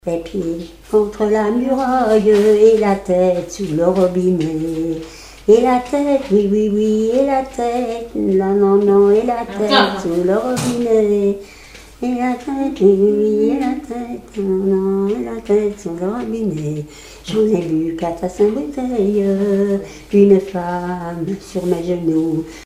circonstance : bachique
Genre strophique
Chansons et témoignages
Pièce musicale inédite